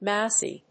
音節mous・ey 発音記号・読み方
/mάʊsi(米国英語)/